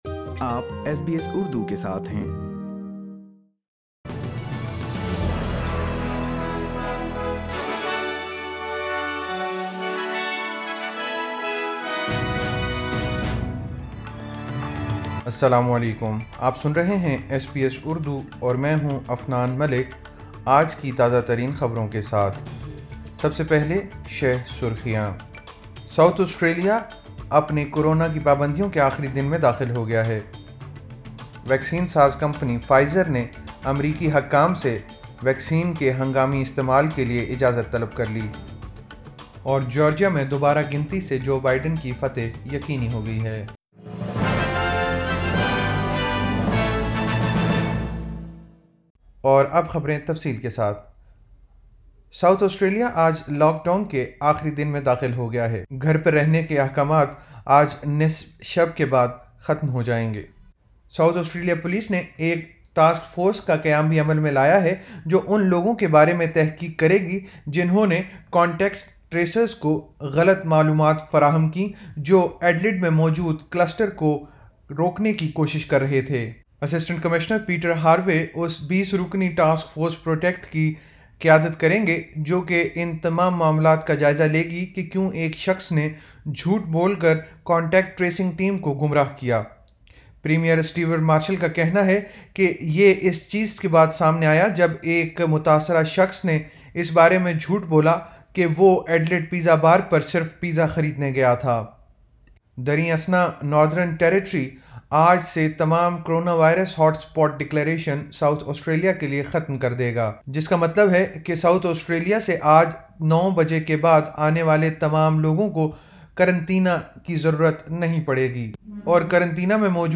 ایس بی ایس اردو خبریں 21 نومبر 2020